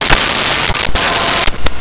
Computer Beeps 02
Computer Beeps 02.wav